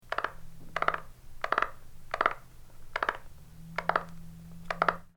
The vocalizations of Rana pretiosa can be described as a quiet series of rapid lo-pitched clicks or knocks.
Release Calls
Sound This is a 5 second recording of the weaker release calls of a sub-adult frog.